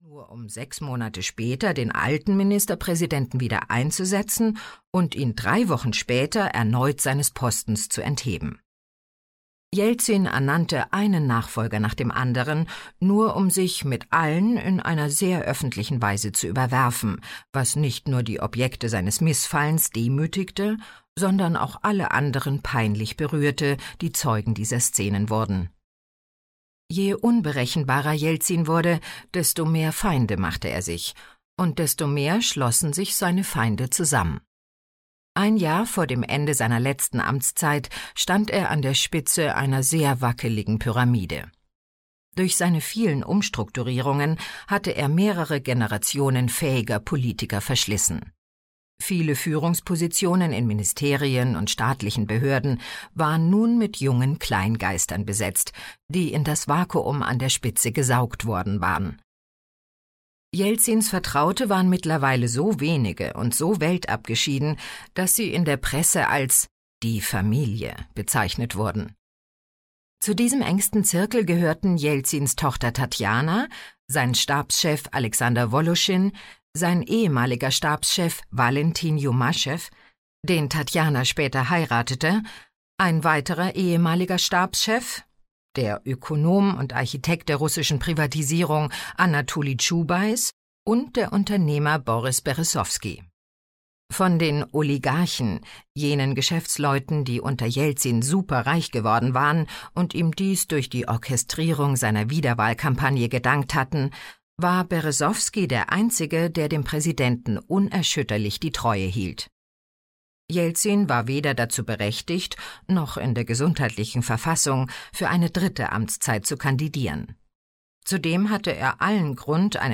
Hörbuch Der Mann ohne Gesicht, Masha Gessen.